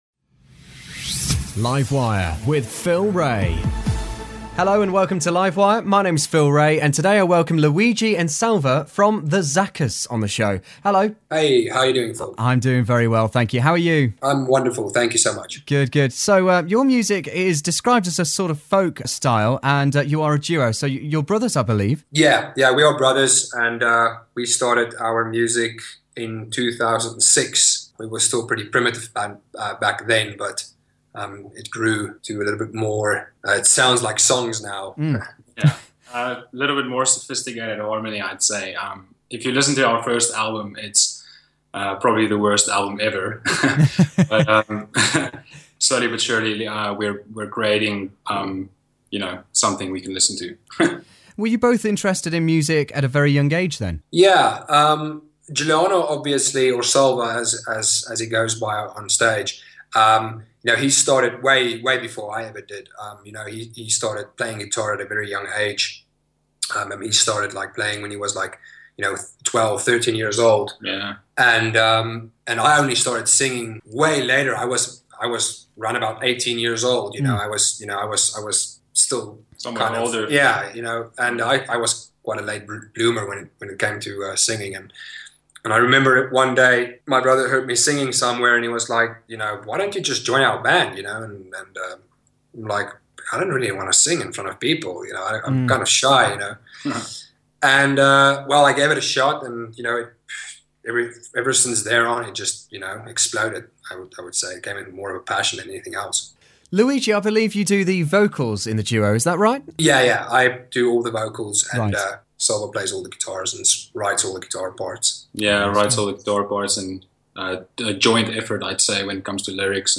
South African singer - songwriter acoustic duo